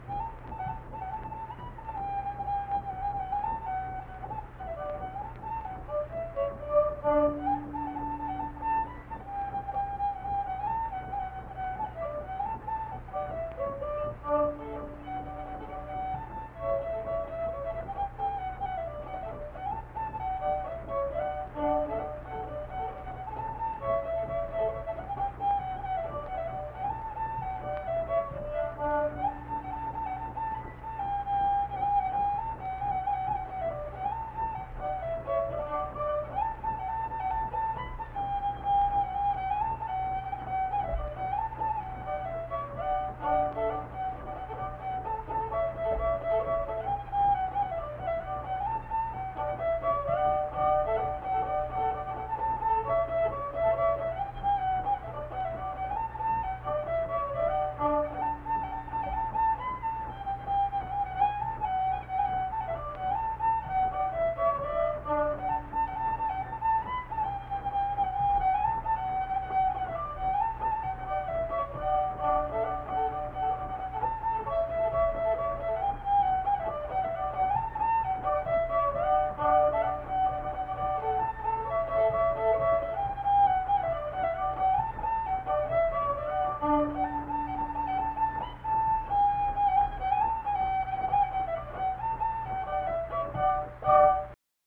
Unaccompanied fiddle music performance
Instrumental Music
Fiddle
Harrison County (W. Va.)